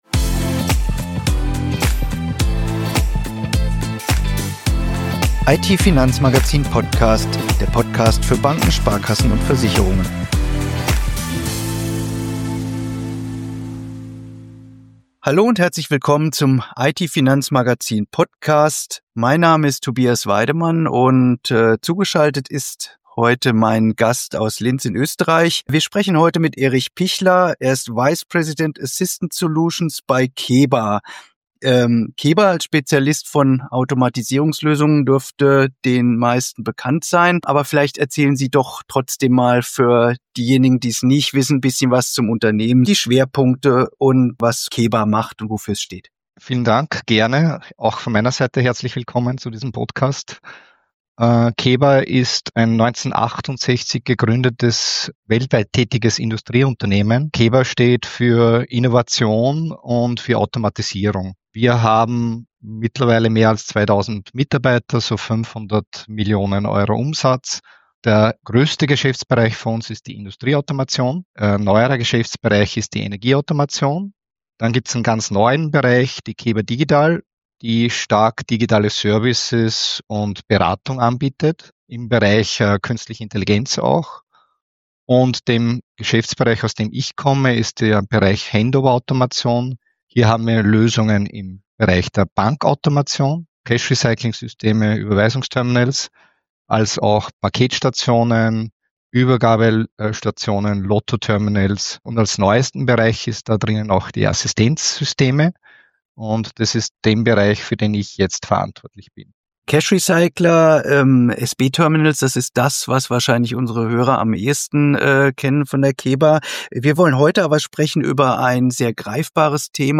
In dieser Folge des IT-Finanzmagazin Podcasts sprechen wir darüber, welche Rolle Künstliche Intelligenz dabei spielen kann – jenseits von Chatbots und generativer KI.